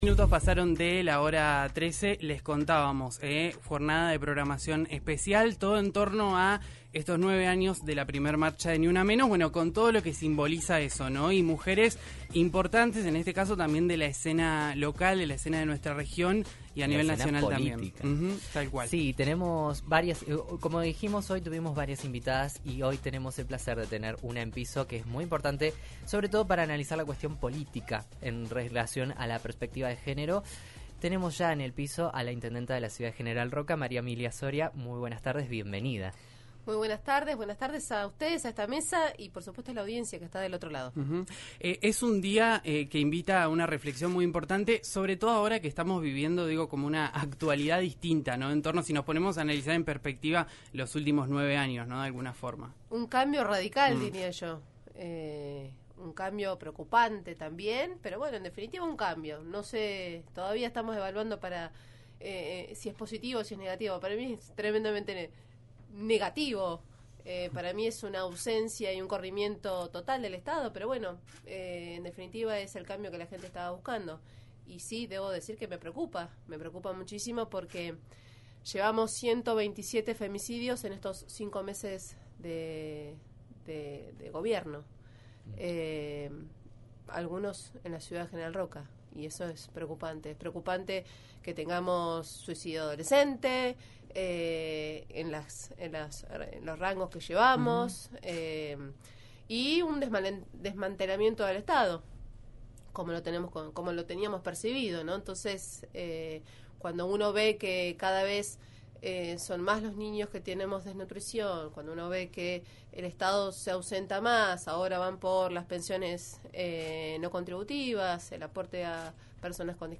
La intendenta de Roca visitó los estudios de RÍO NEGRO RADIO, con motivo de la jornada por NiUnaMenos. Analizó el rol de la mujer en la escena política, habló sobre la actualidad del Gobierno nacional y contó detalles sobre la obra pública.